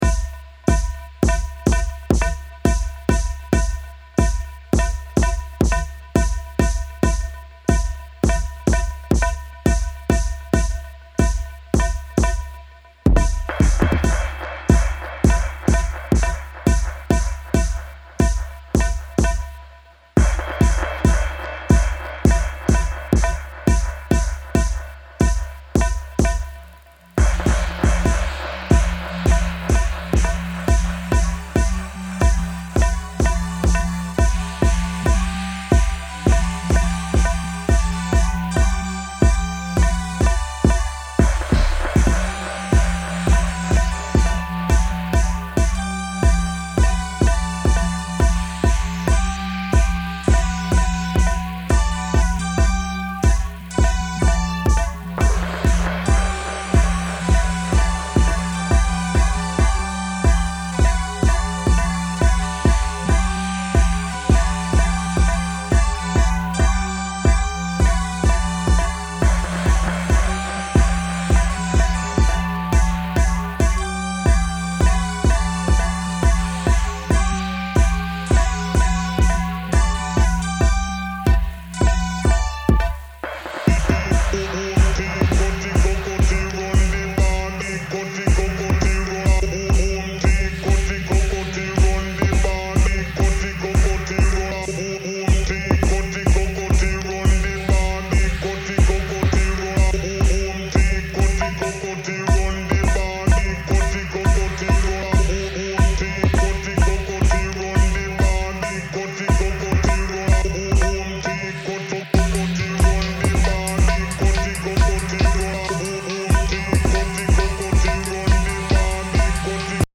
abstract Dub & Synth experiments